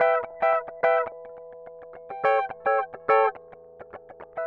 Retro Funkish Guitar 01c.wav